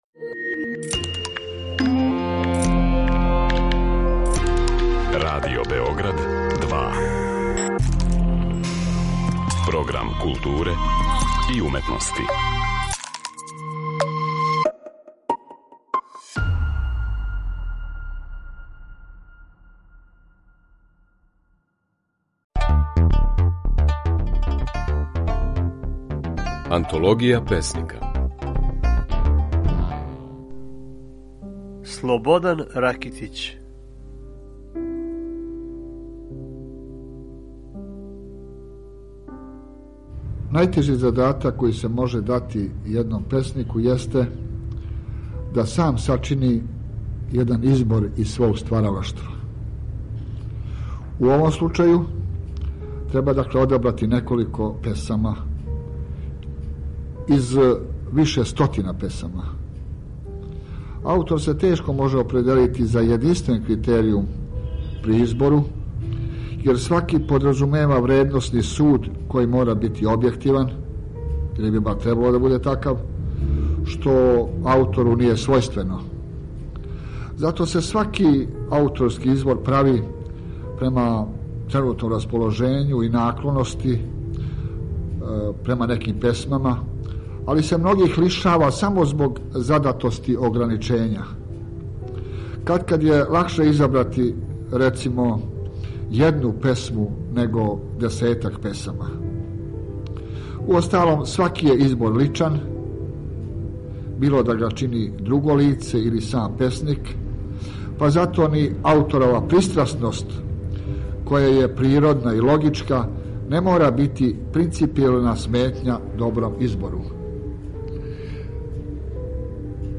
Можете чути како je своје стихове говориo наш познати песник Слободан Ракитић (1940, Власово код Рашке - 2013, Београд).